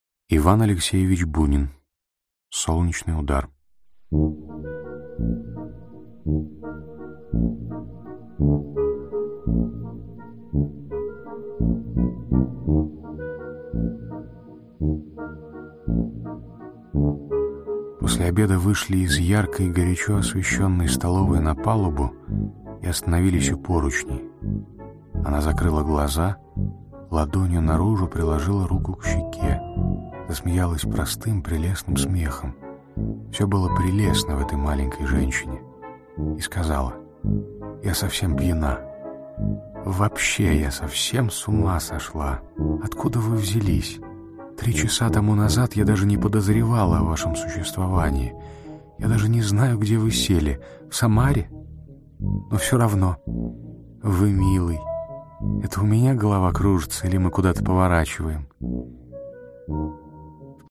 Аудиокнига Солнечный удар. рассказ | Библиотека аудиокниг
Aудиокнига Солнечный удар. рассказ Автор Иван Бунин Читает аудиокнигу Егор Бероев.